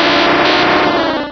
Cri de Gravalanch dans Pokémon Rubis et Saphir.